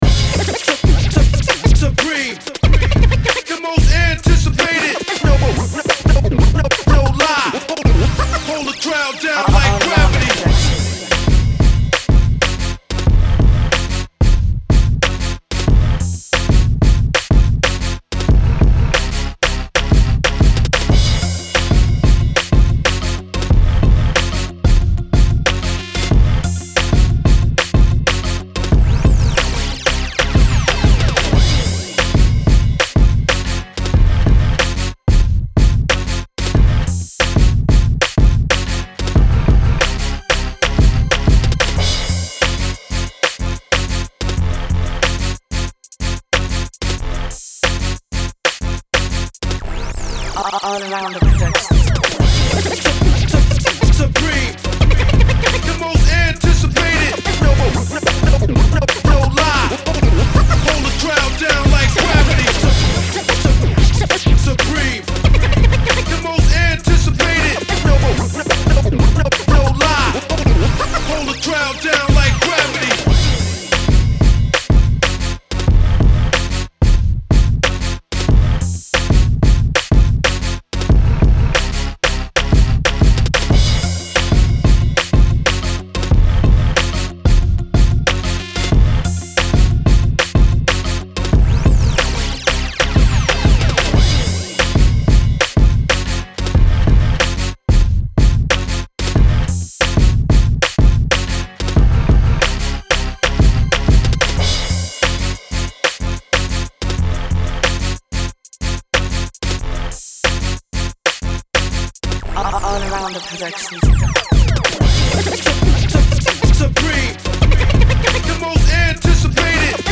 ловите лучшие 10 минусов, пряных, качающих, сочных.
минус 5 кач ваще))